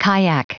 Prononciation du mot kayak en anglais (fichier audio)
Prononciation du mot : kayak